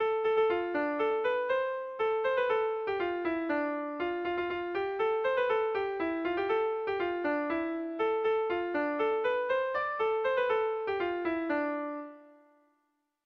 Kontakizunezkoa
Senpere < Lapurdi Erdialdea < Lapurdi < Euskal Herria
ABDAB